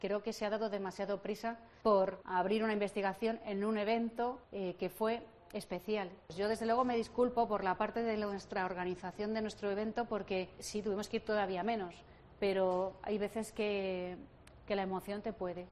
Isabel Diaz Ayuso durante el cierre de IFEMA